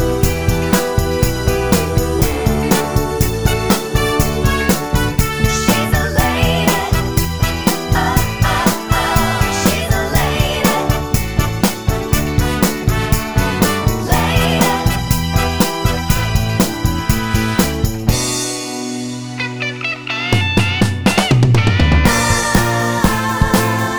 With Live Ending Pop (1960s) 2:56 Buy £1.50